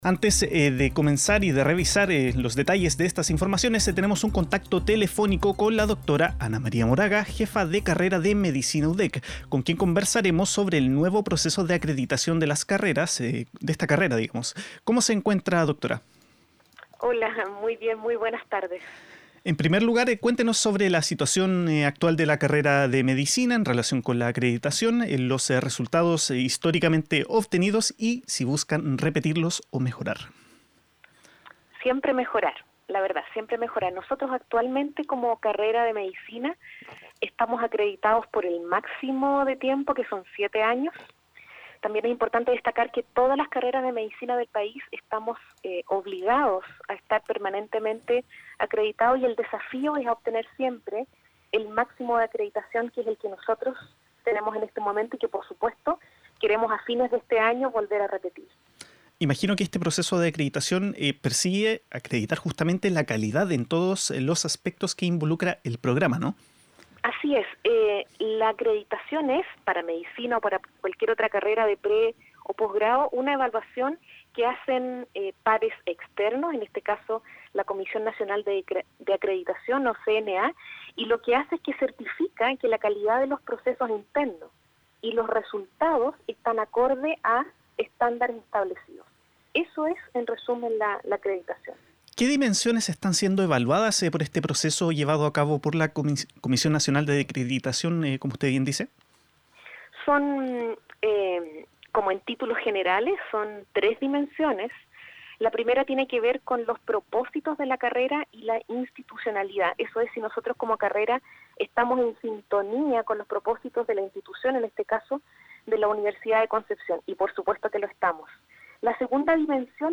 entrevista-13-hrs-14-enero.mp3